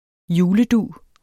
Udtale [ -ˌduˀ ]